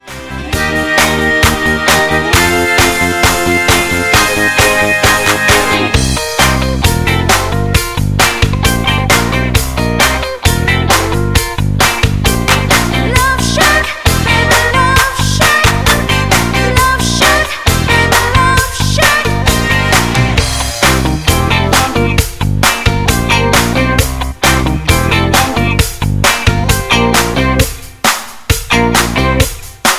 Backing track Karaoke
Pop, Rock, 1980s